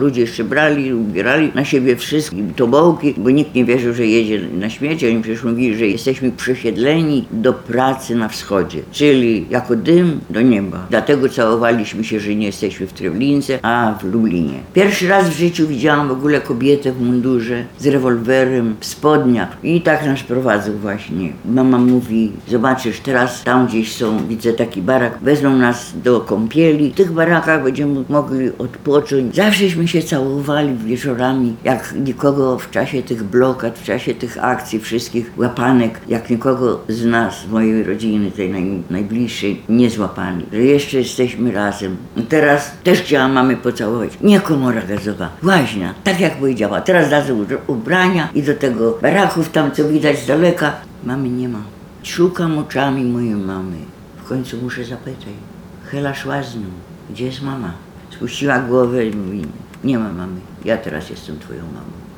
Zabrakło gazu – wspominała dziś (21.04) w Muzeum na Majdanku 93-letnia Halina Birenbaum, która przeżyła powstanie w getcie warszawskim.